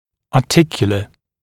[ɑː’tɪkjulə][а:’тикйулэ]суставной